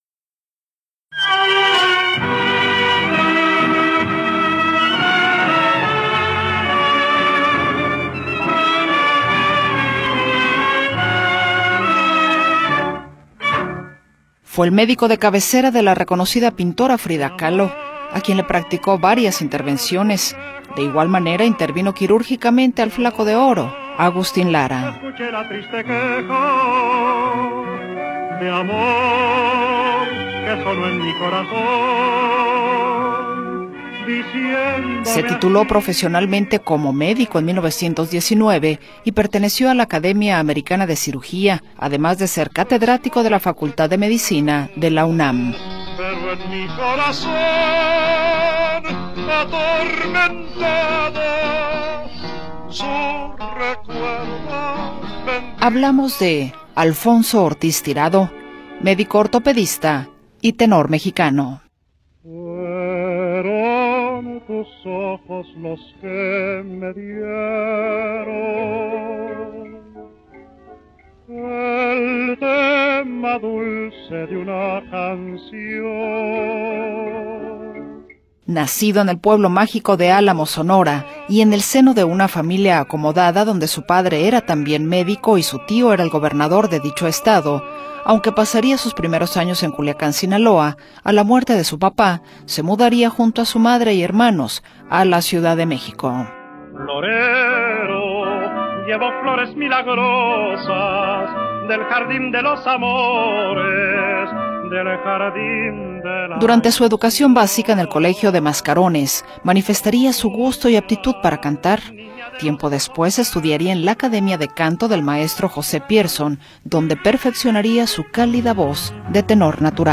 Alfonso Ortiz Tirado fue un médico y cantante mexicano, conocido como el “Tenor de las Américas”.
Ortiz Tirado se convirtió en una figura emblemática de la música romántica, interpretando boleros, tangos y canciones rancheras. Su voz cálida y melodiosa lo llevó a triunfar tanto en México como en escenarios internacionales.